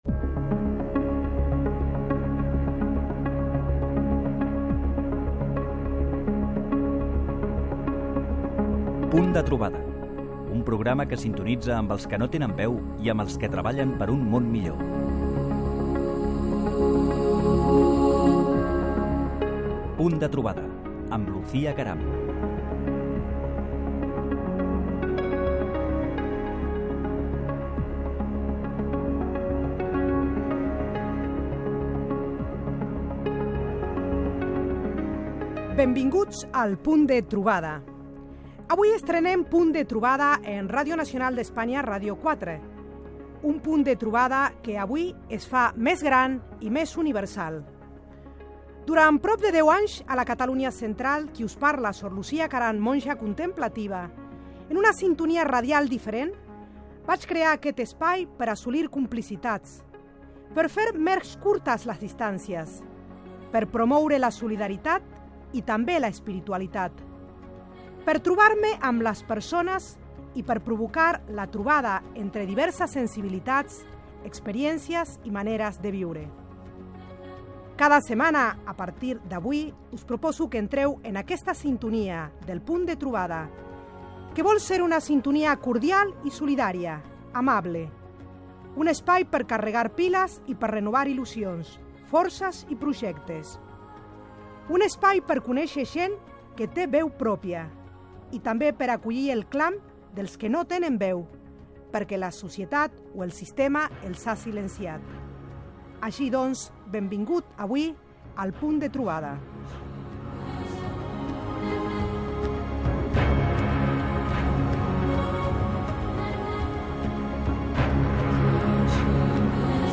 Careta, presentació i objectius del programa.
Gènere radiofònic Entreteniment